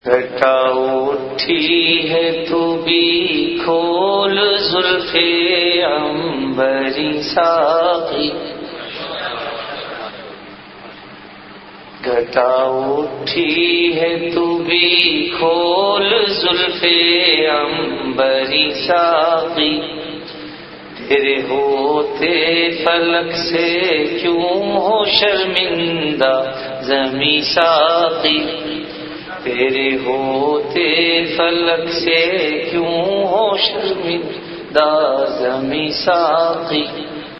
CategoryAshaar
VenueJamia Masjid Bait-ul-Mukkaram, Karachi
Event / TimeAfter Isha Prayer